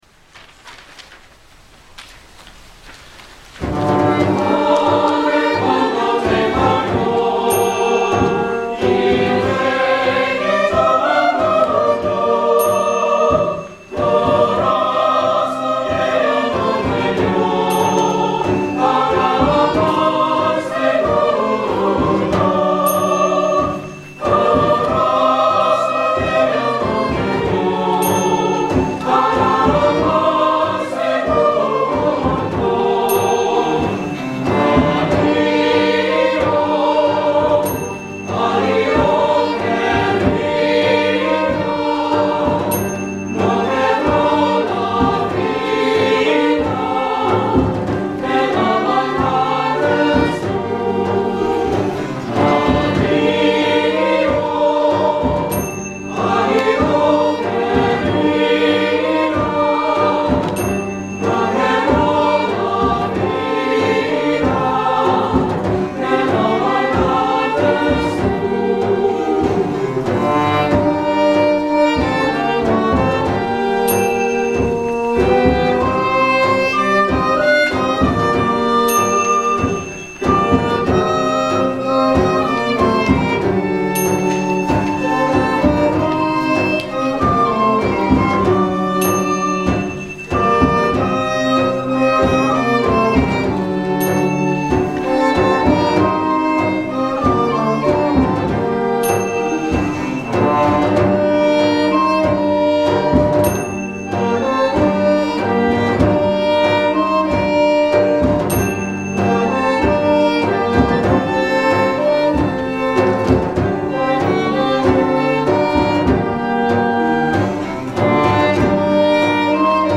Nashville Early Music Ensemble - Final Performance - Mar. 30, 2010
7) Adió Querida - Sephardic Folk Song - YouTube -